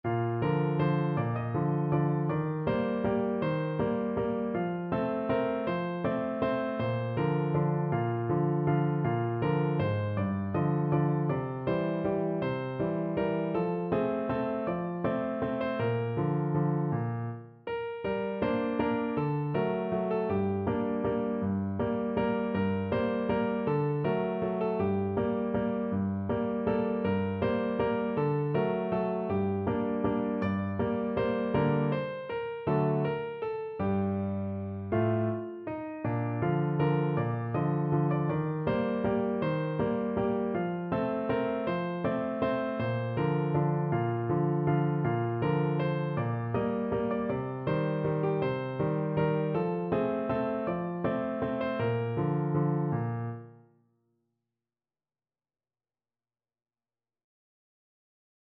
No parts available for this pieces as it is for solo piano.
Bb major (Sounding Pitch) (View more Bb major Music for Piano )
3/4 (View more 3/4 Music)
~ = 160 Tempo di Valse
Piano  (View more Easy Piano Music)
Traditional (View more Traditional Piano Music)
flying_trapeze_PNO.mp3